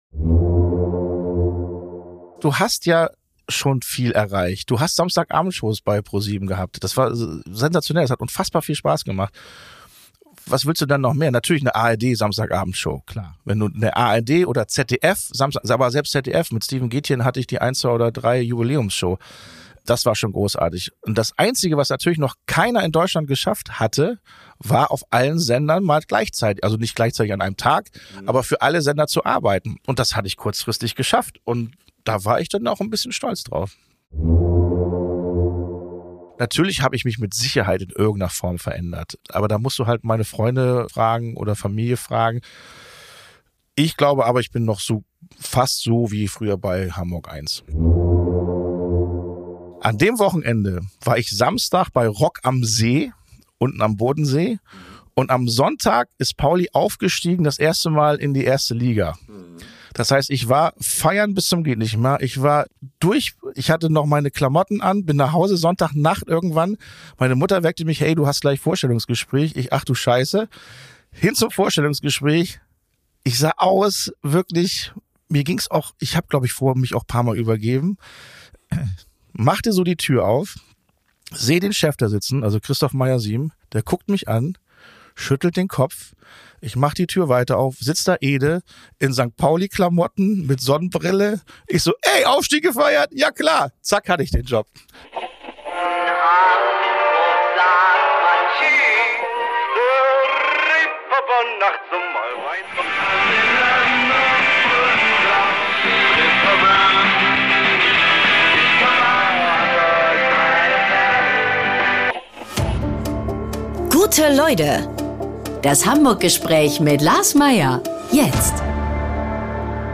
Radiosendung